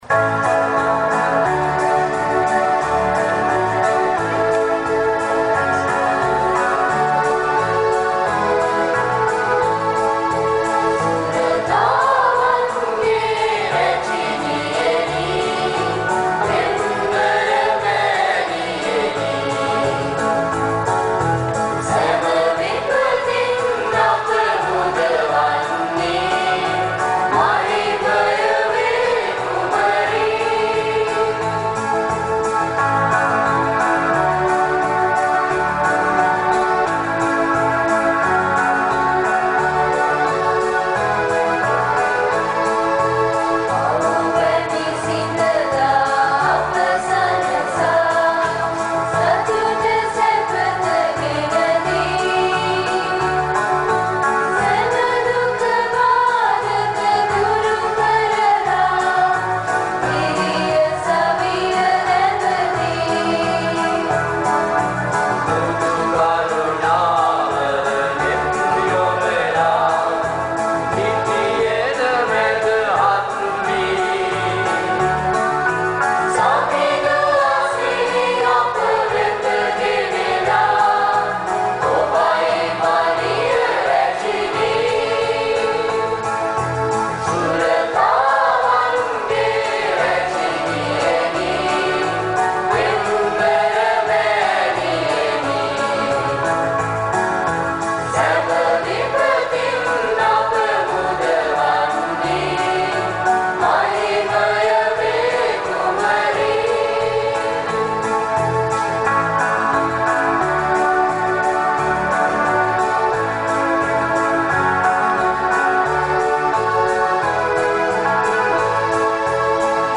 for the festive high mass